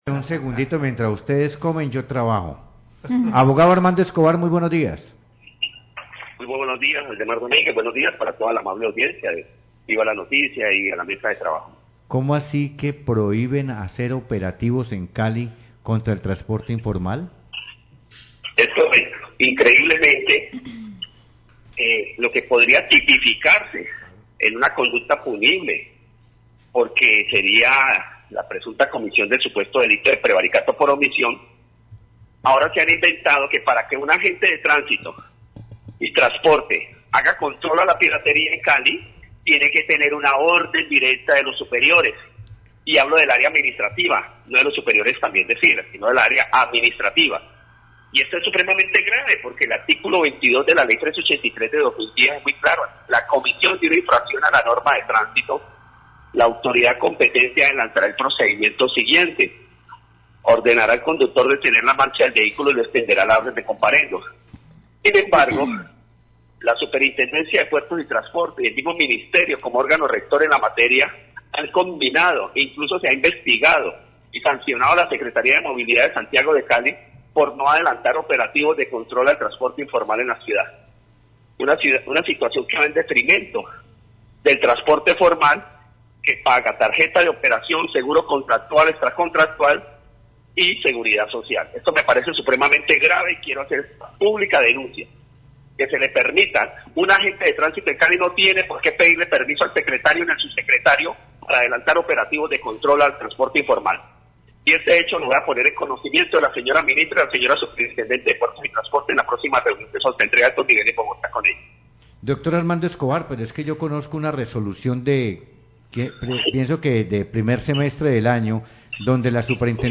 Radio
Concejal Roberto Rodríguez habla sobre las vigencias futuras que solicitó el alcalde Armitage al Concejo de Cali.